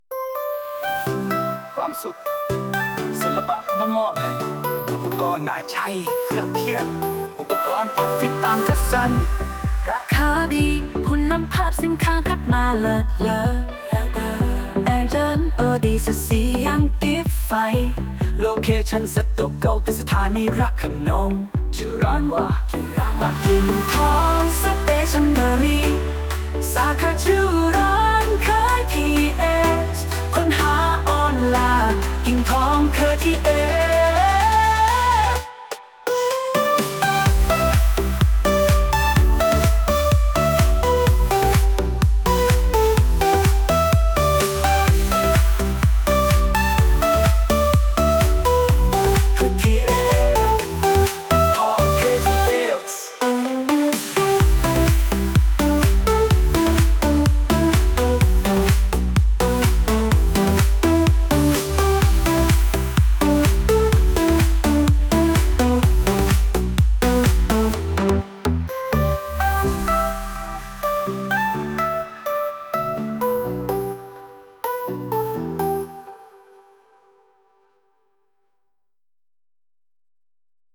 Kingthong-Music-Pop-Electronic.mp3